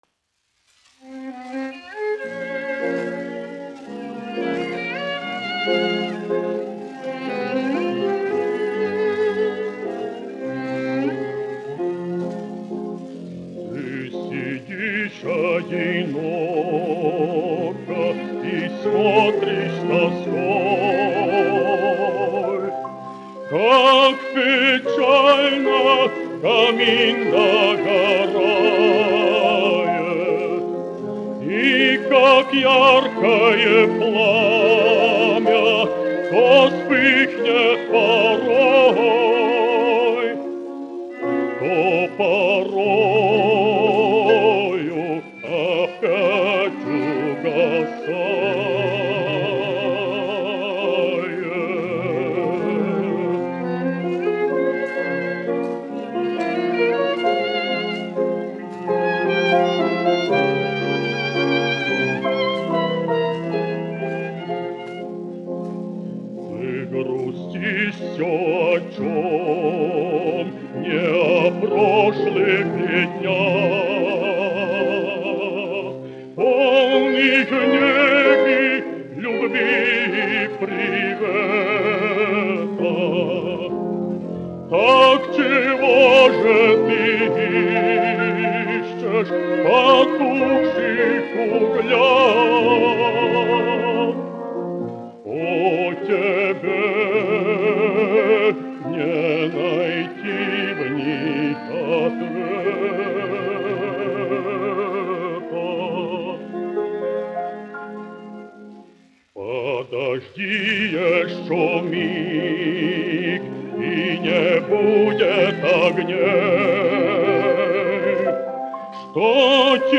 1 skpl. : analogs, 78 apgr/min, mono ; 25 cm
Dziesmas (zema balss) ar instrumentālu ansambli
Romances (mūzika)
Latvijas vēsturiskie šellaka skaņuplašu ieraksti (Kolekcija)